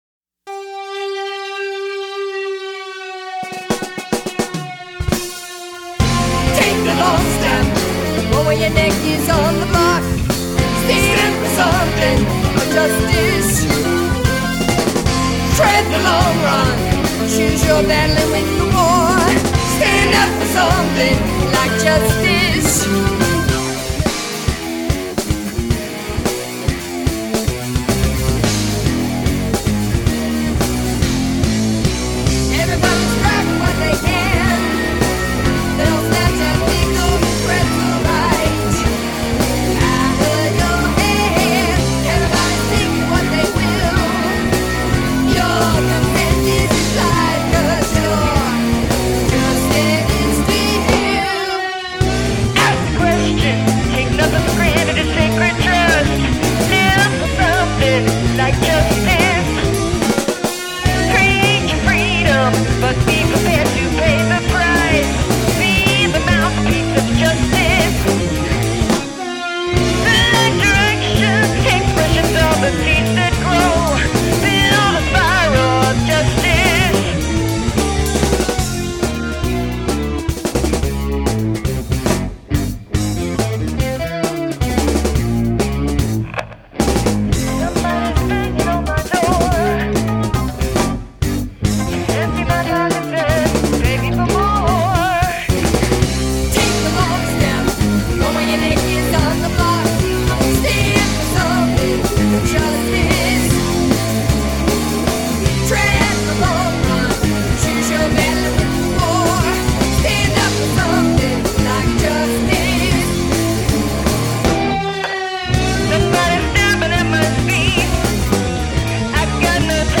keyboards, backing vocals
guitar
bass
drums, backing vocals
lead and backing vocals